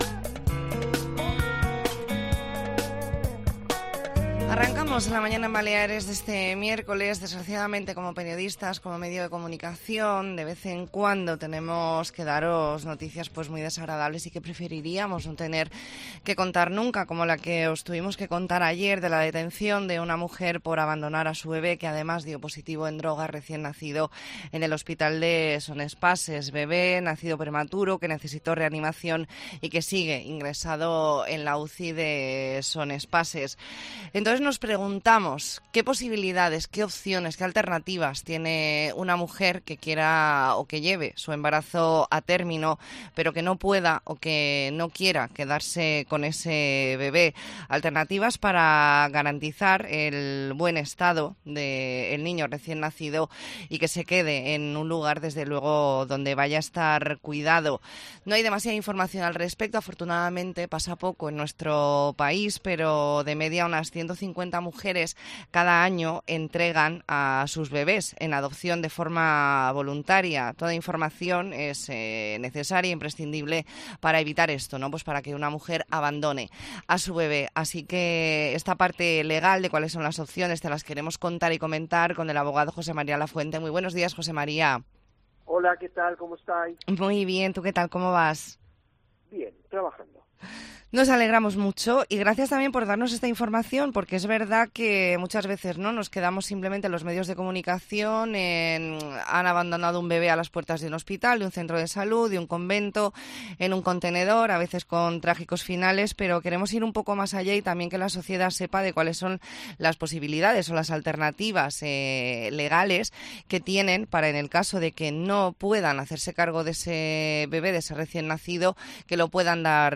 Entrevista en La Mañana en COPE Más Mallorca, miércoles 11 de octubre de 2023.